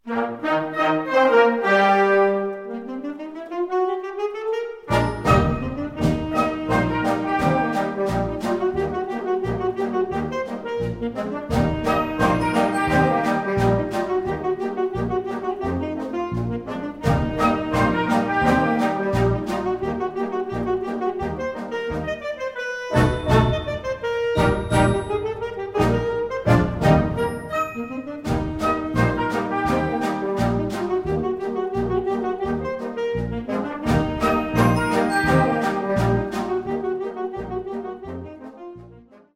Solo z orkiestrą Tagi
rozrywkowa